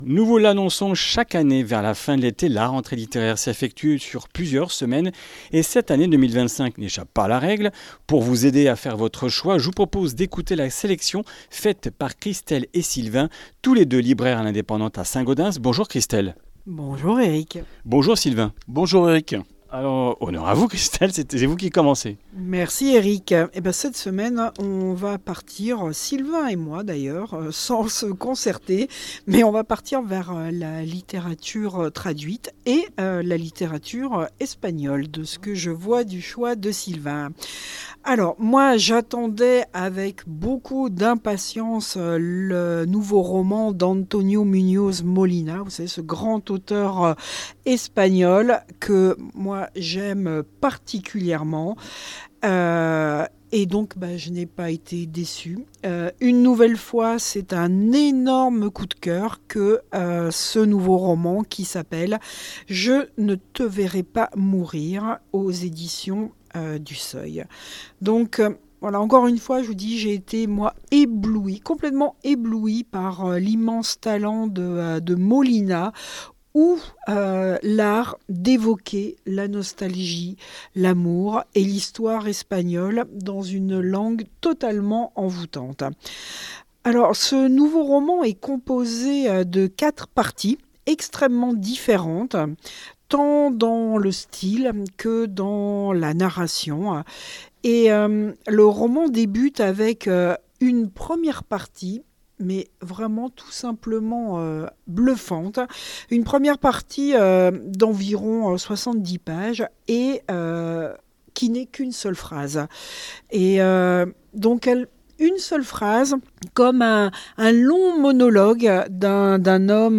Comminges Interviews du 12 sept.